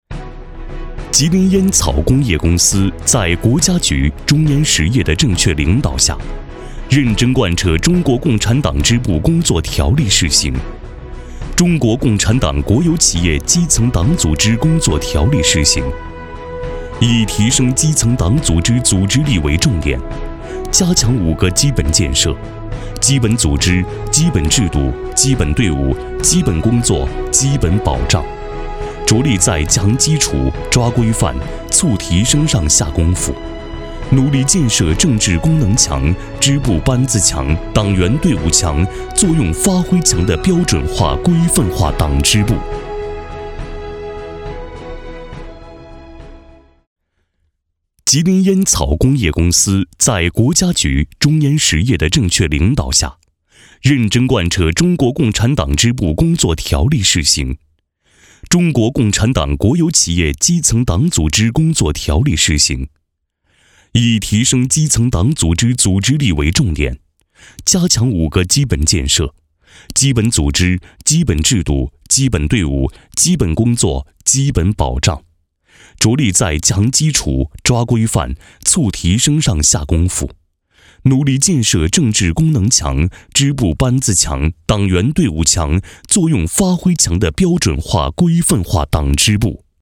特点：大气浑厚 稳重磁性 激情力度 成熟厚重
风格:磁性配音